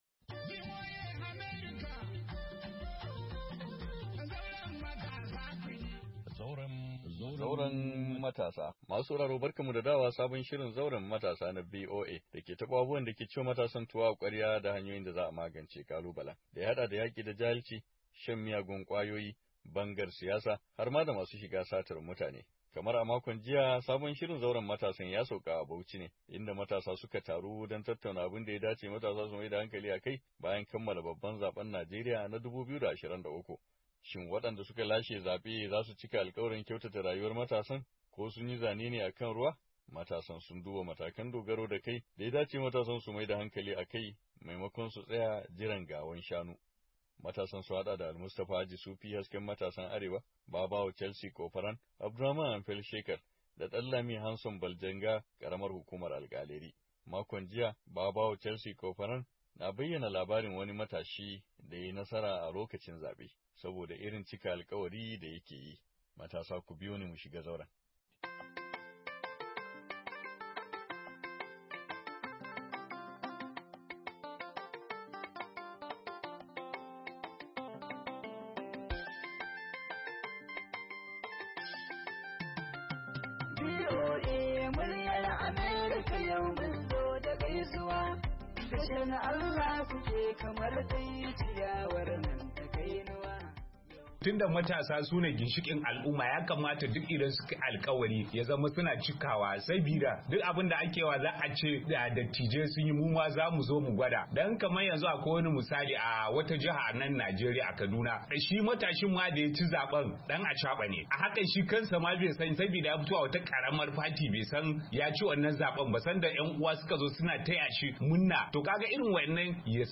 ABUJA, NIGERIA - A cikin shirin na wannan makon ci gaba ne a jihar Bauchi inda matasa su ka taru don tattauna abun da ya da ce su maida hankali a kai bayan kammala babban zaben Najeriya na 2023.